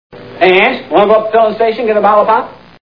The Andy Griffith TV Show Sound Bites